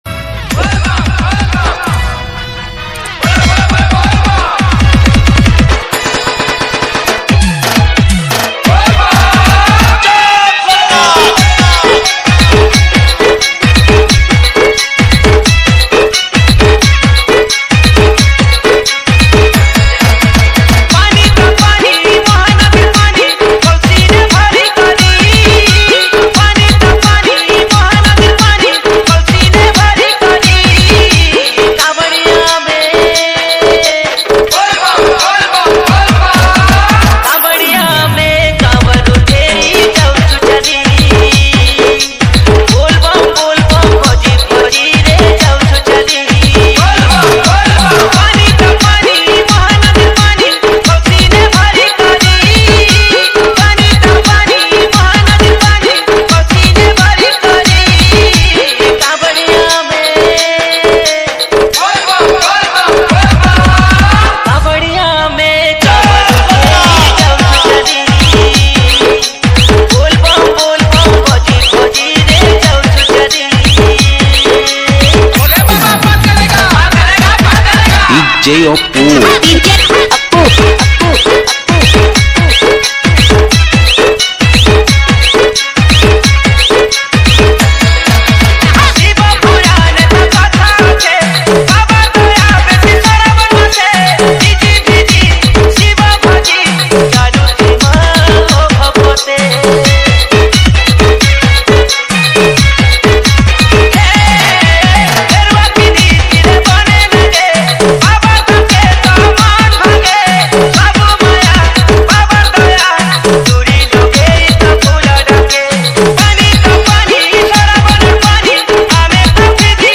ODIA BHAJAN DJ REMIX